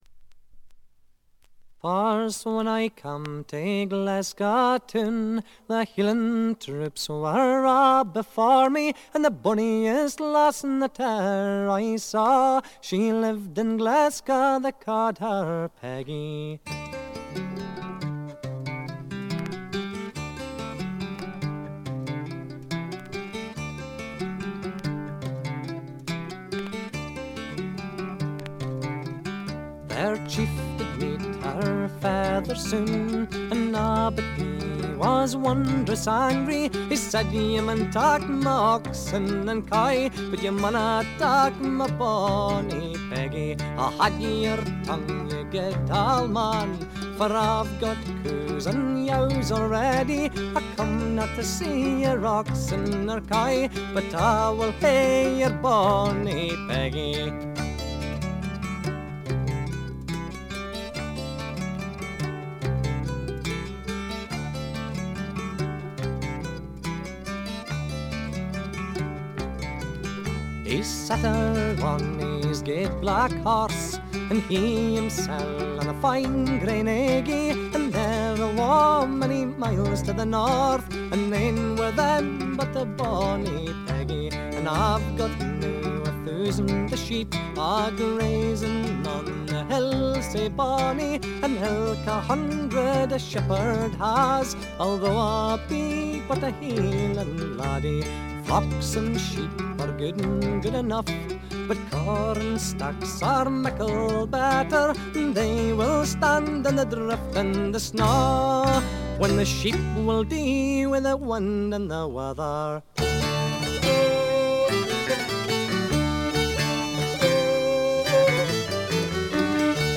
70年にエディンバラで結成されたスコットランドを代表するトラッド・バンド。
試聴曲は現品からの取り込み音源です。
Fiddle, viola, bouzouki, mandolin, mandola, vocals
Guitar, mandola